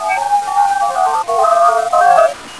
recorded at the battlefield.
Many others appeared when I filtered out the sound of the breeze.
However....I think I may have recorded the ghostly chant of a
you can hear different voices overlapping.
"Indian Chant!"